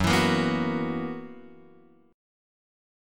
F# 11th